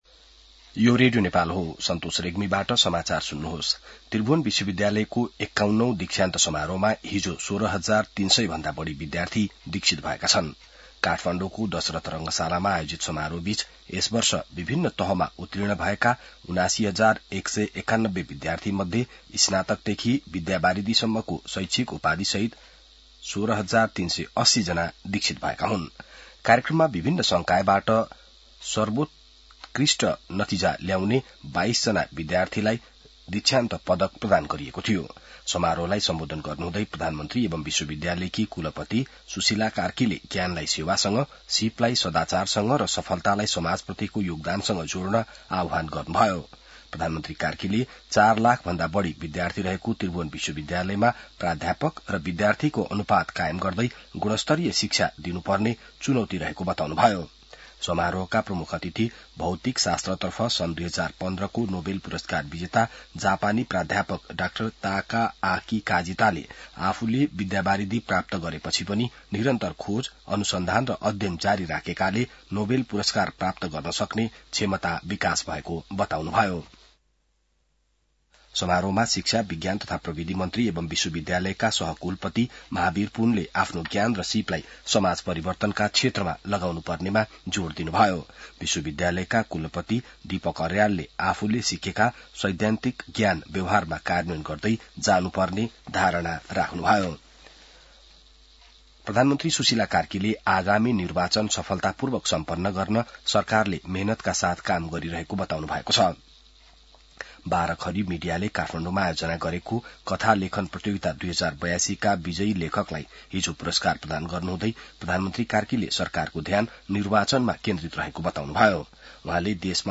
बिहान ६ बजेको नेपाली समाचार : ११ पुष , २०८२